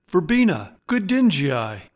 Pronounciation:
Ver-BEE-na good-DIN-gi-i